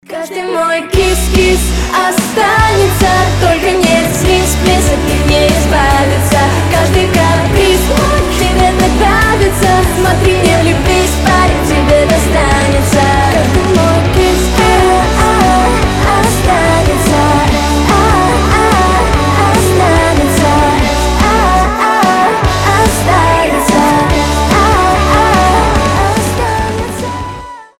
• Качество: 320, Stereo
молодежные
поп-панк